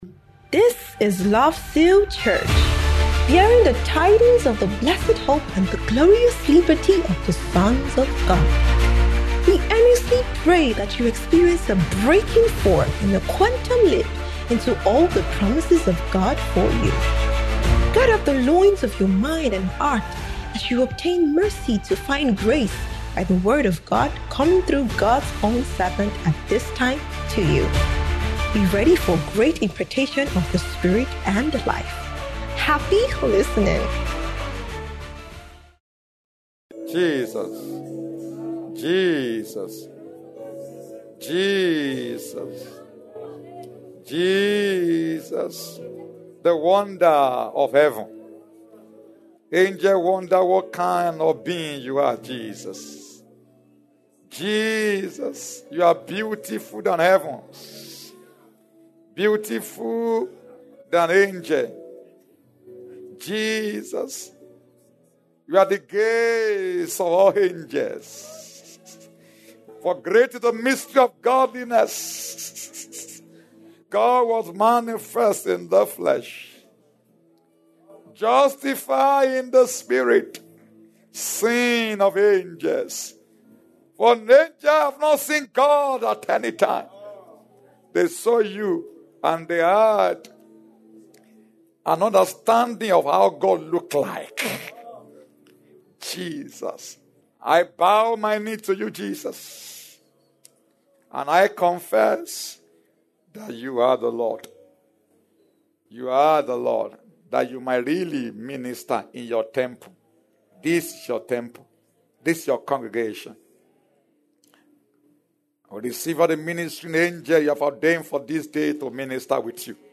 Spirit Life Reign 2025 - Grand Finale